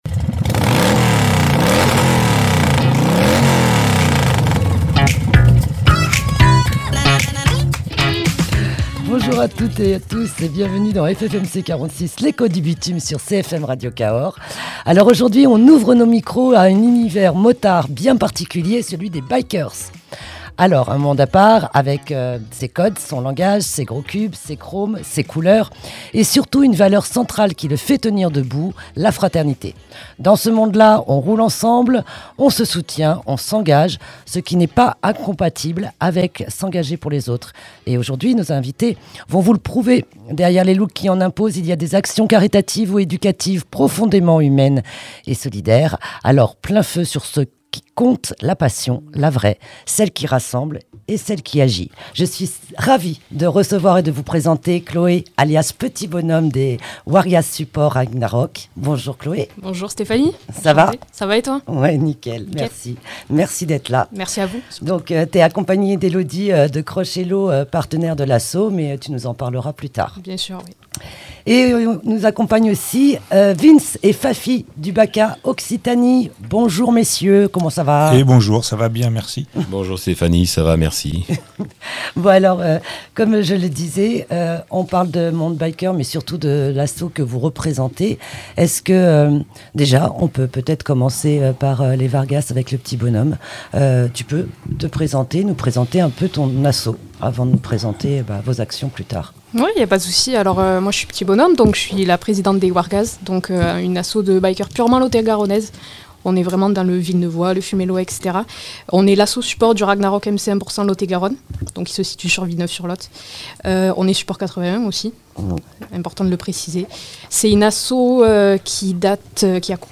Emission spéciale Bikers Harcèlement et Handicap